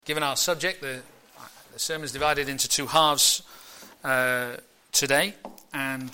Media Library Media for a.m. Service on Sun 14th Sep 2014 10:30 Speaker
Theme: The Holy Spirit - Salvation and Sanctification Sermon In the search box below, you can search for recordings of past sermons.